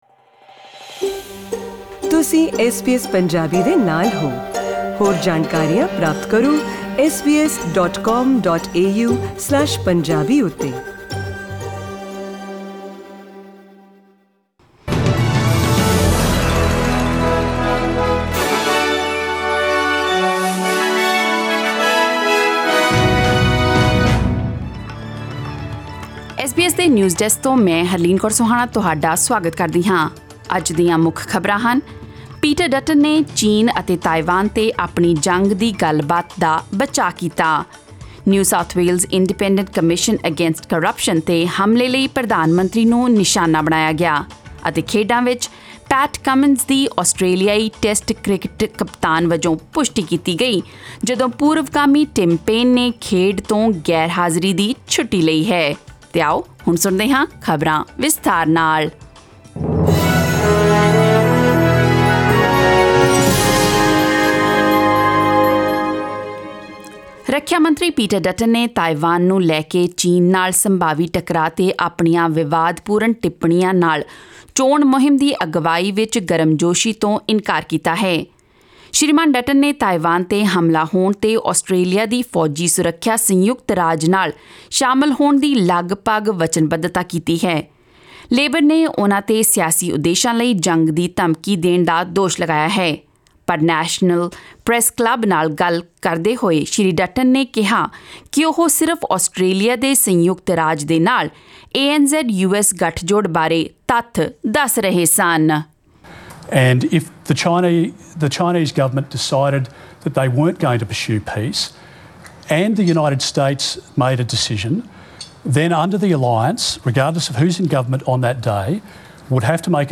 Listen to the latest news headlines in Australia from SBS Punjabi radio